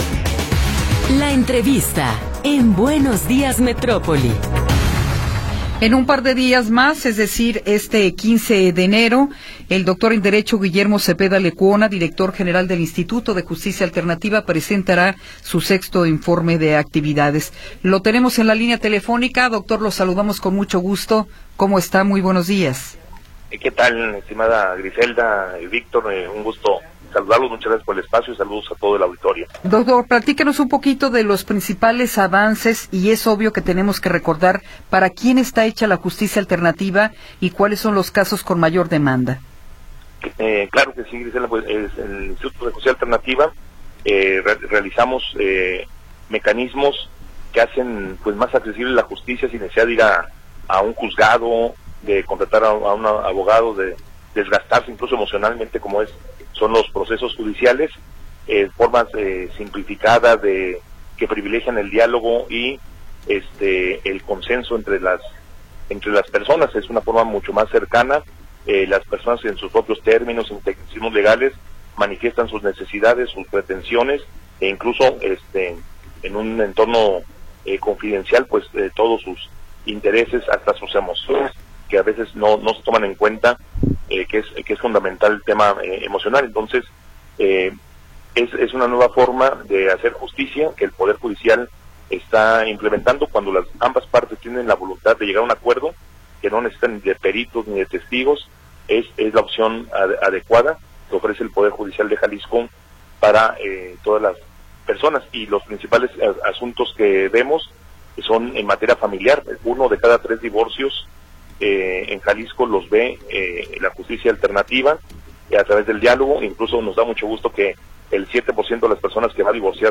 Entrevista con Guillermo Zepeda Lecuona